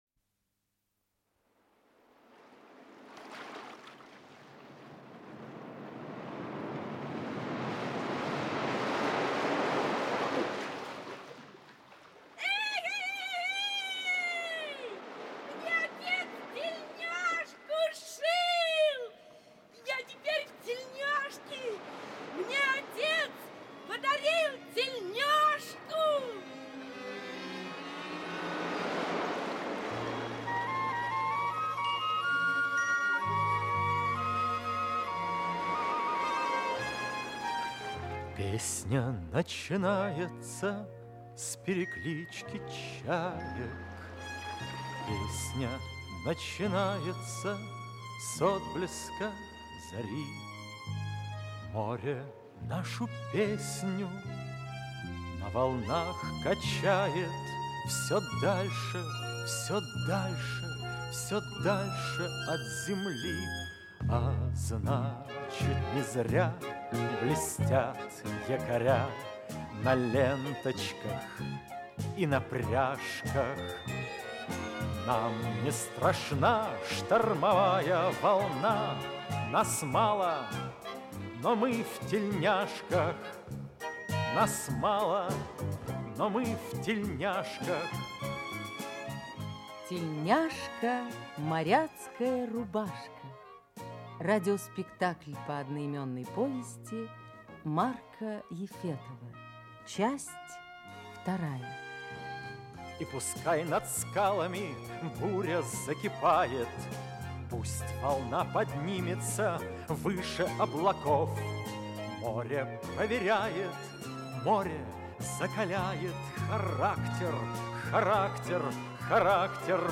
Аудиокнига Тельняшка – моряцкая рубашка. Часть 2 | Библиотека аудиокниг